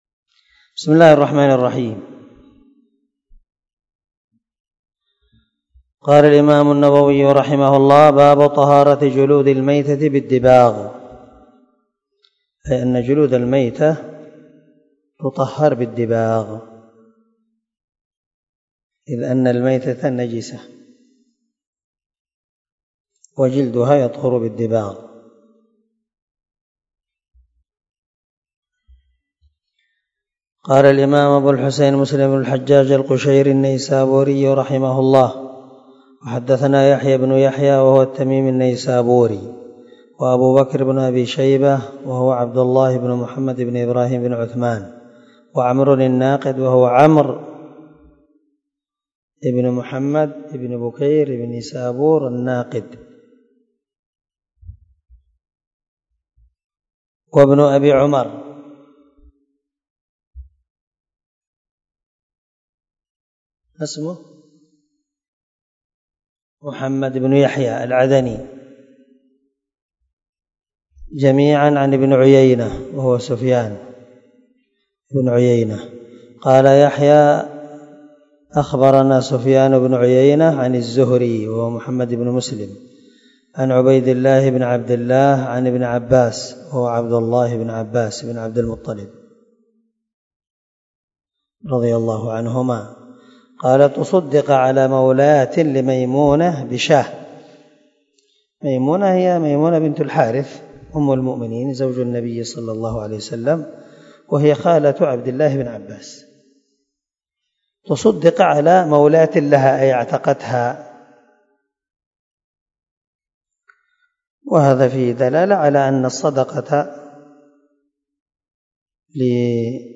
سلسلة_الدروس_العلمية